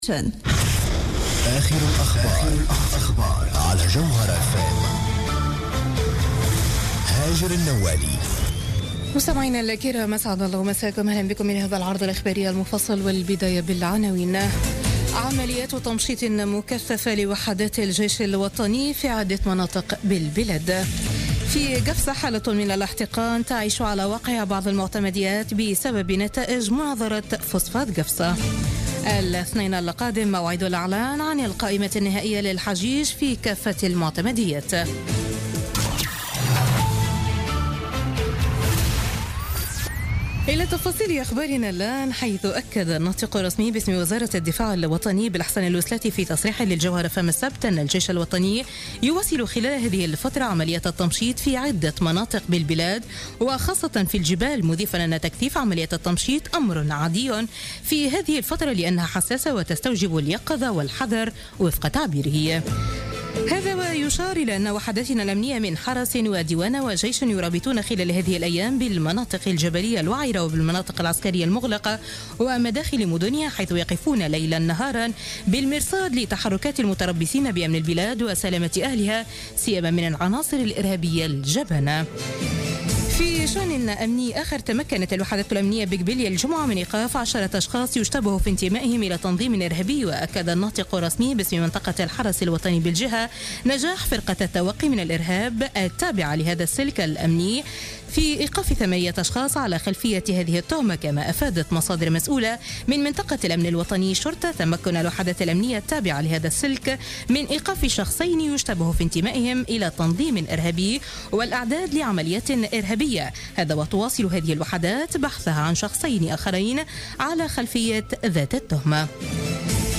نشرة أخبار منتصف الليل ليوم الأحد 3 جويلية 2016